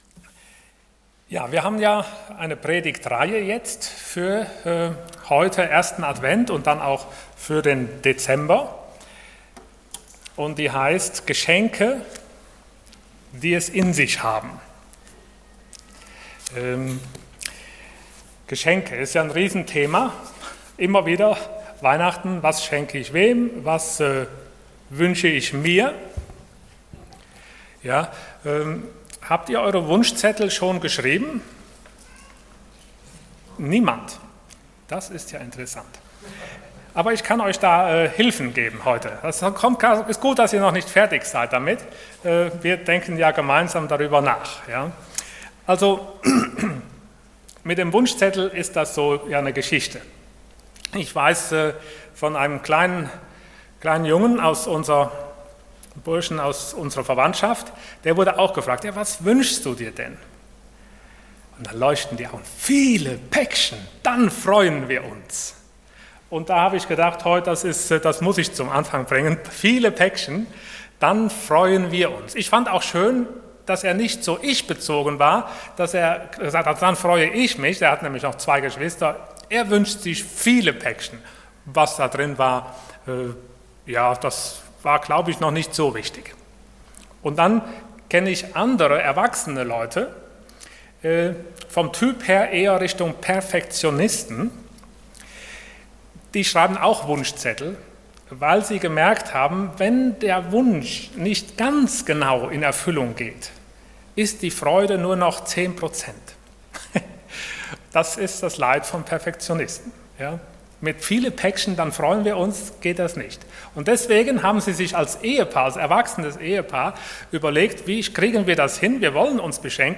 Dienstart: Sonntag Morgen